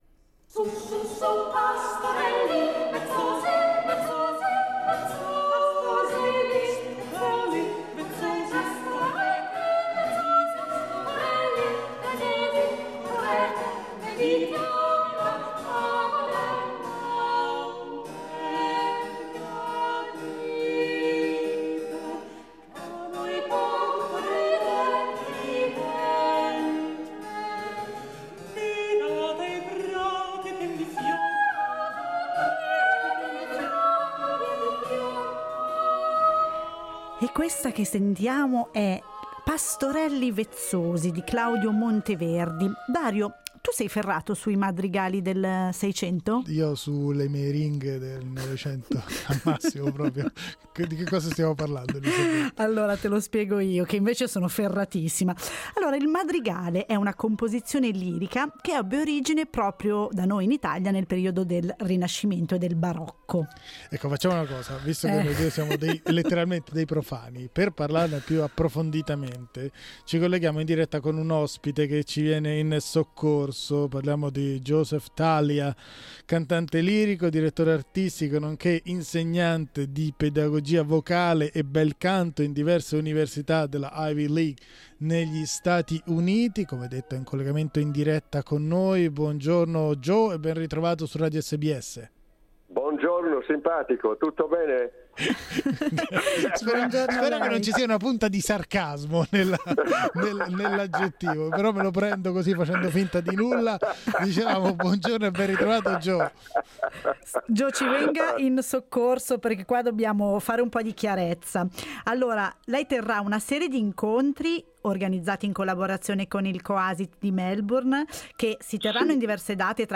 Ascolta l'intervista: LISTEN TO Alla scoperta dei madrigali